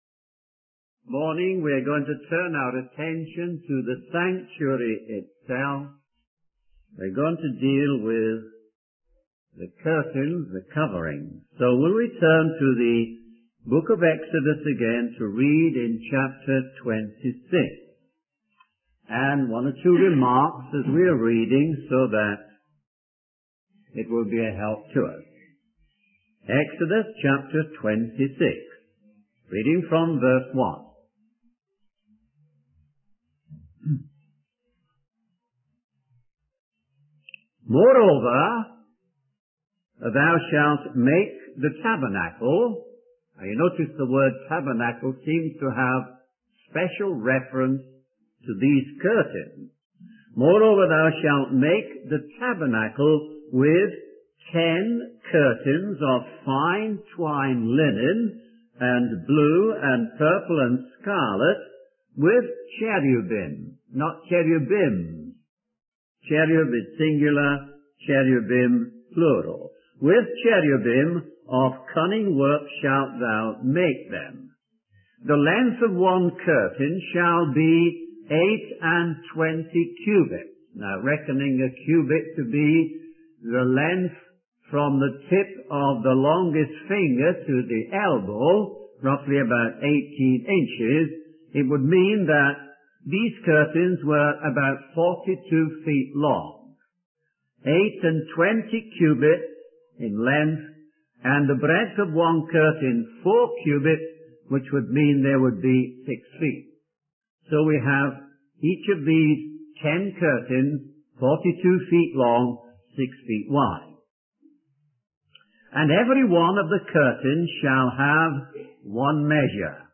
In this sermon, the speaker discusses the tabernacle as a model of what was in the wilderness. He explains that the tabernacle represents Christ personally and corporately.